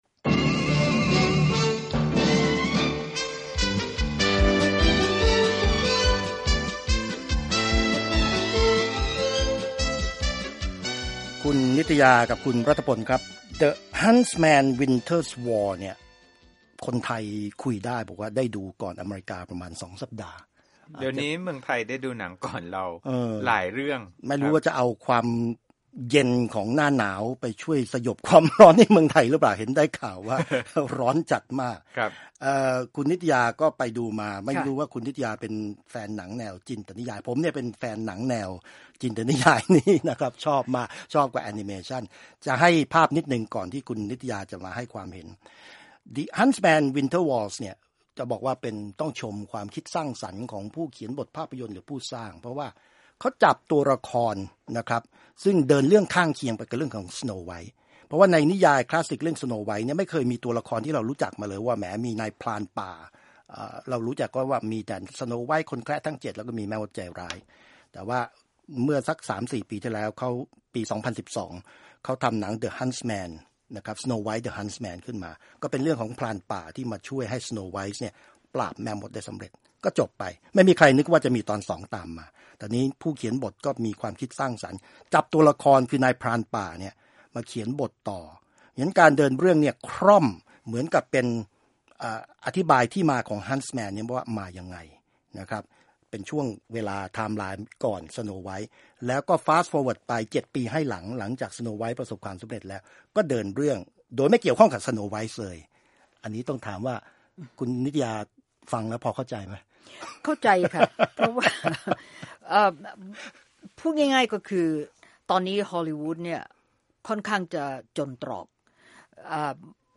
ร่วมคุยหนังเรื่องนี้ จ่กส่วนหนึ่งของรายการสุดสัปดาห์กับวีโอเอ)